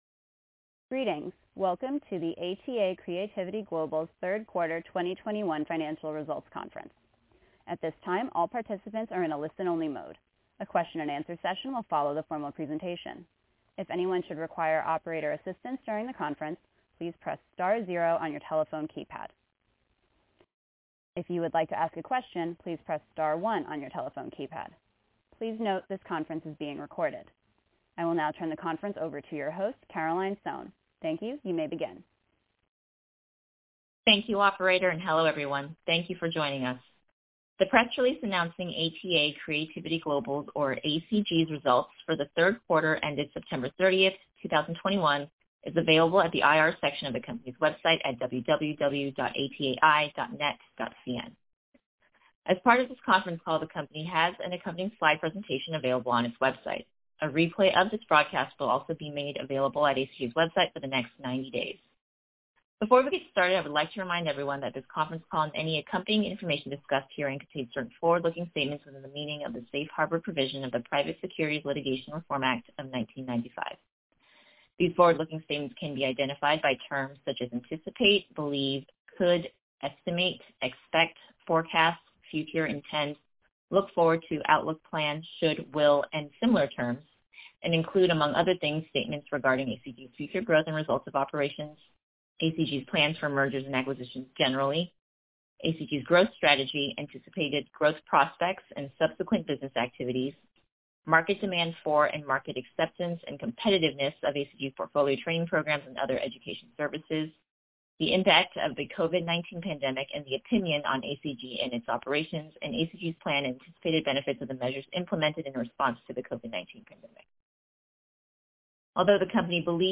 Q3 Fiscal Year 2021 Earnings Conference Call